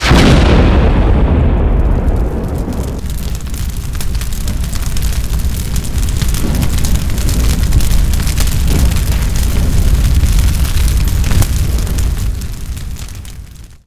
“Fiery Inferno Cast” Created in Sound Booth CS4 By